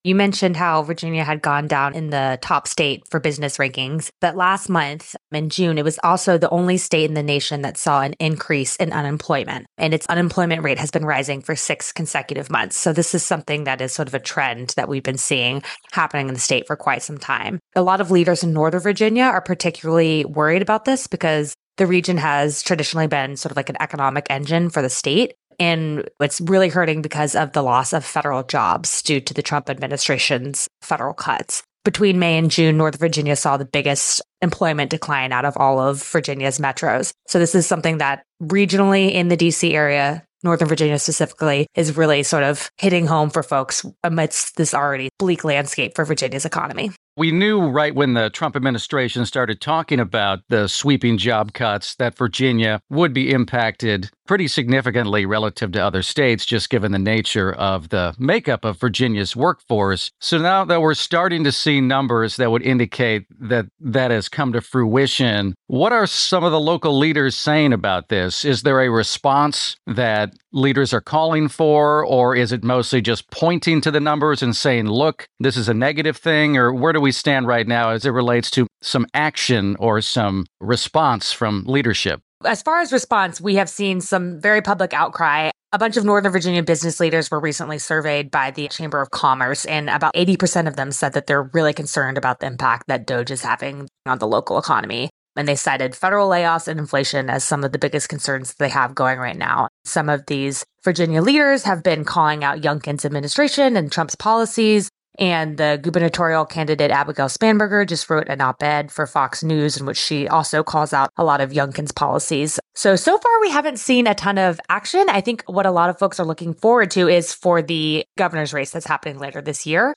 Q&A: Federal job cuts may lead to reckoning and reinvention in Virginia